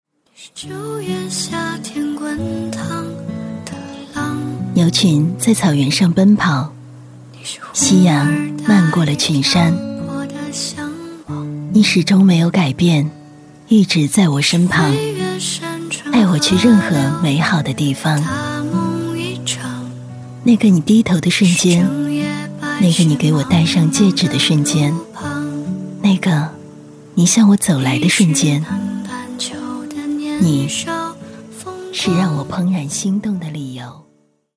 女国163_其他_微电影_唯美婚礼独白.mp3